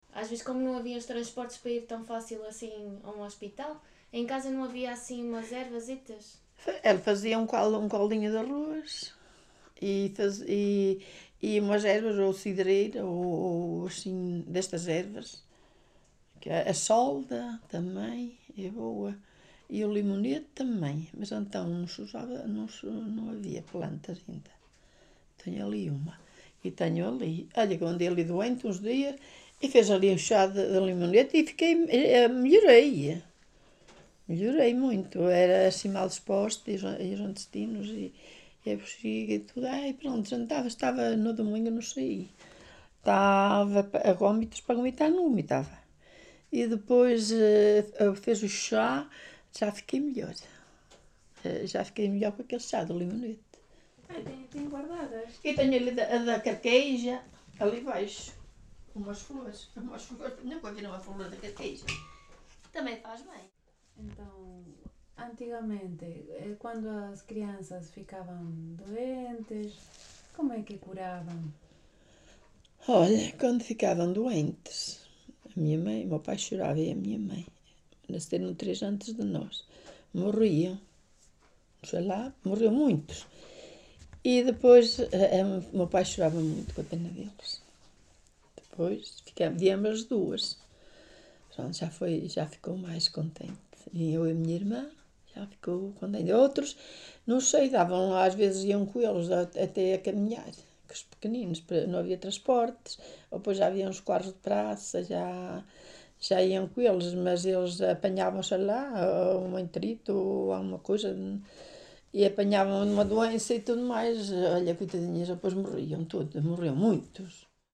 Várzea de Calde, primavera de 2019.
Tipo de Prática: Inquérito Oral